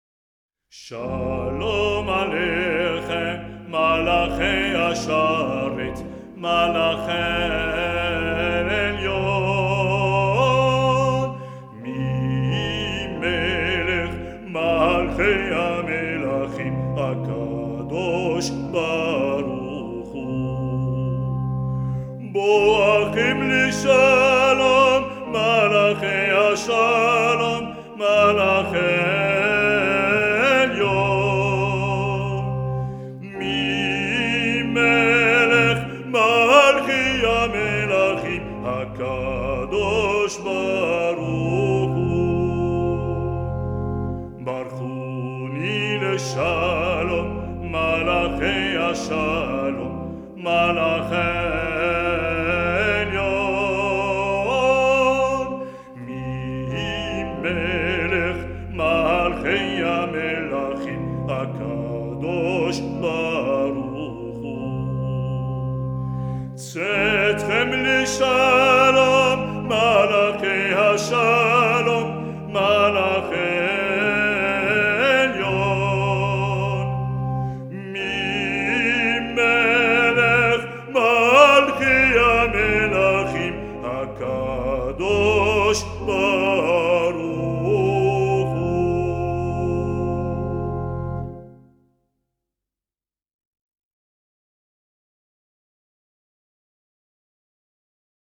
Chalom alékhem est un hymne que les Juifs ont coutume de chanter avant le Kiddouch du vendredi soir.